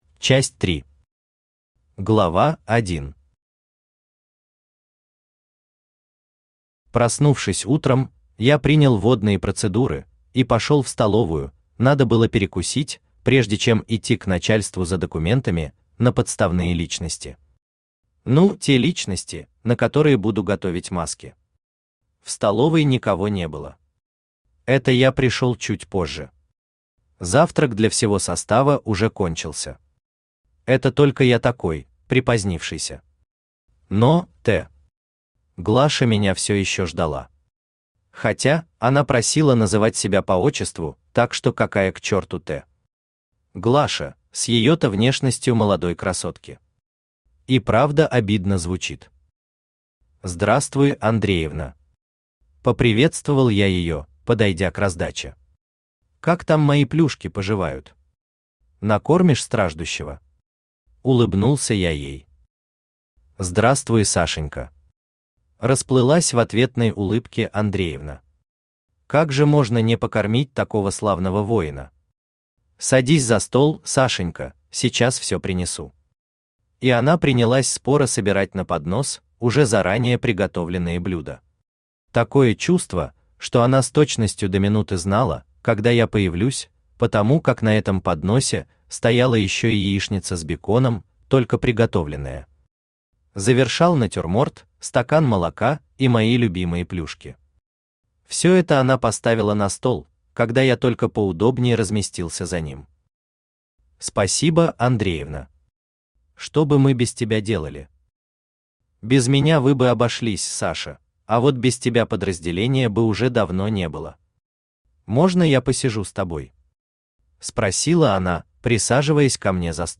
Аудиокнига Есть только миг… «Зенит» | Библиотека аудиокниг
Читает аудиокнигу Авточтец ЛитРес